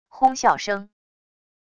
哄笑声wav音频